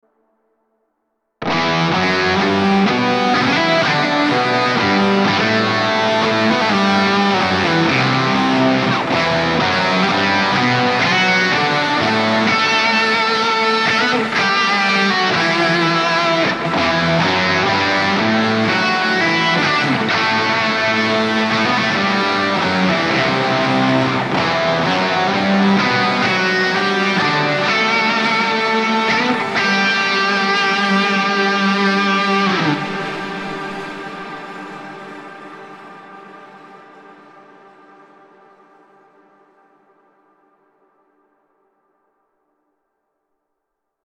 Create great sounding ambient music with just your guitar and Headrush with this pack from NVA.
A. OCTAVE: Toggle the octaver
GRAIN: Toggle the grain delay
RAW AUDIO CLIPS ONLY, NO POST-PROCESSING EFFECTS